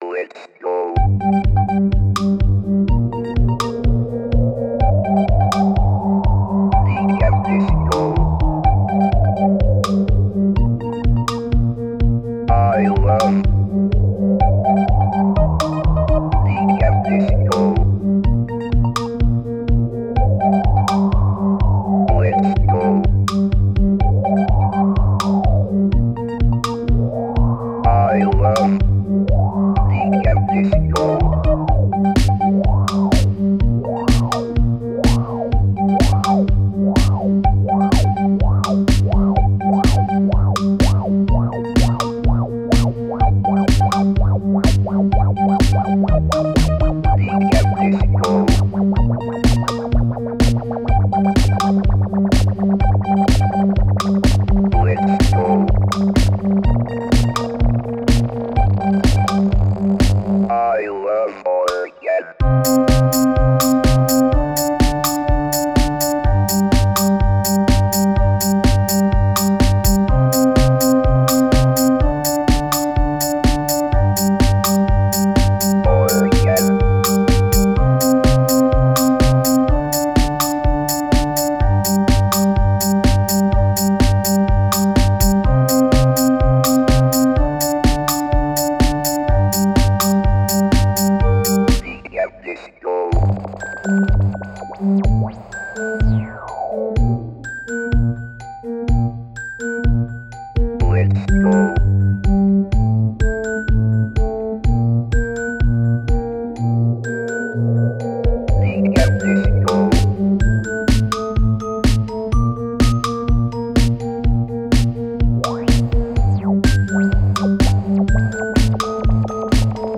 decap-disco.flac